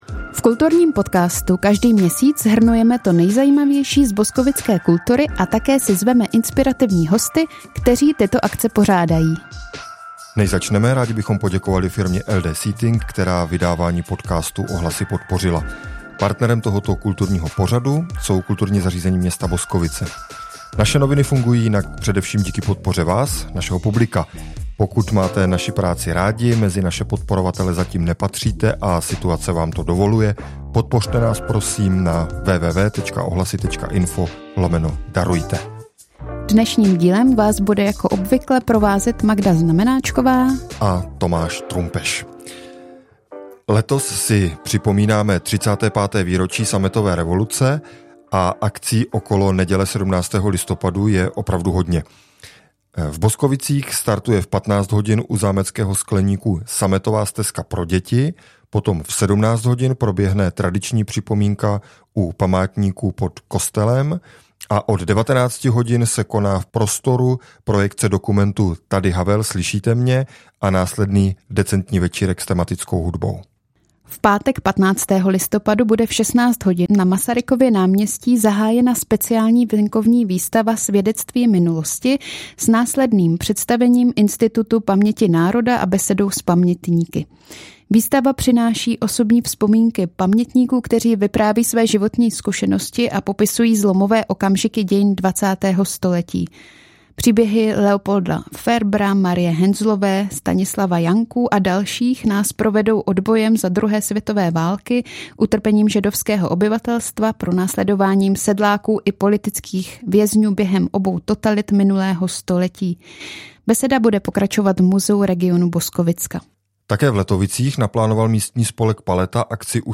V kulturním podcastu každý měsíc shrnujeme to nejzajímavější z boskovické kultury a také si zveme inspirativní hosty, kteří tyto akce pořádají. Tentokrát jsme se rozhodli nevěnovat rozhovor v našem kulturním podcastu některé konkrétní akci, ale chceme se víc bavit o kulturní infrastruktuře, zejména o plánované rekonstrukci sokolovny.